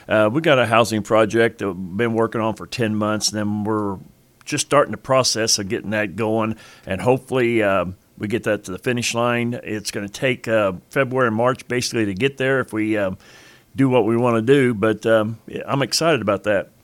Mayor Rhoads says the project has been discussed for nearly a year…